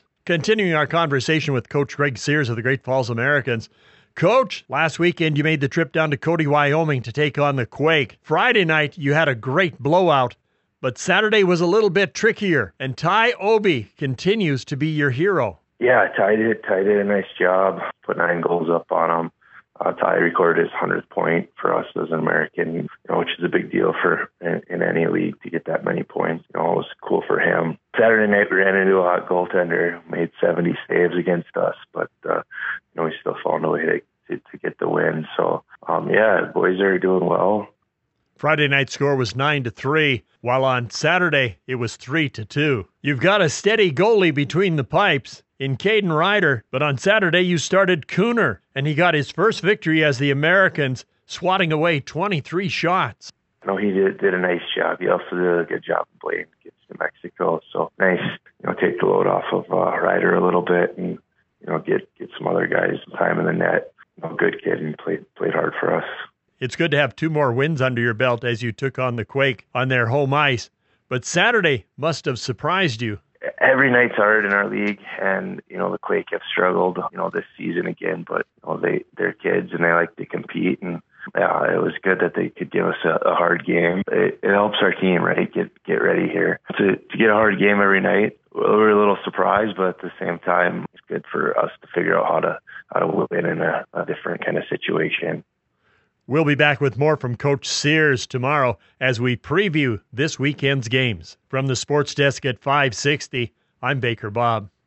560AM KMON: Weekly Radio Interview